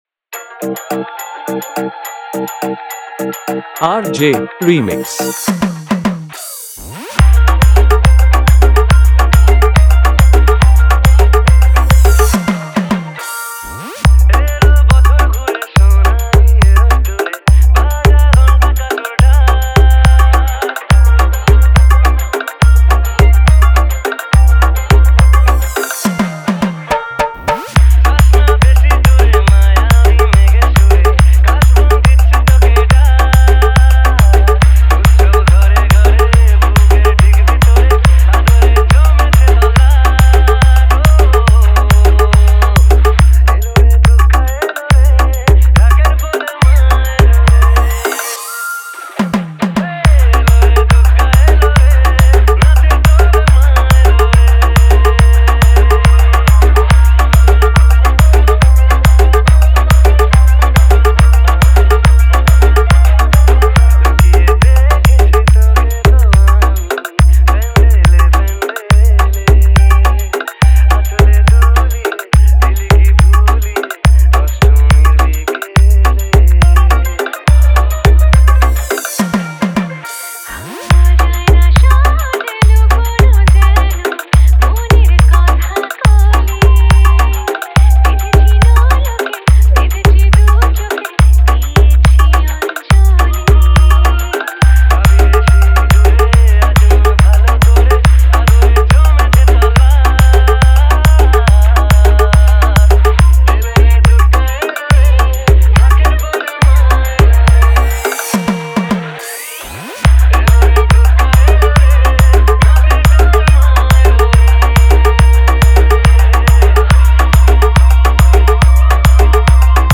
দুর্গ উৎসব 2024 স্পেশাল হামবিং ভক্তি বাংলা গান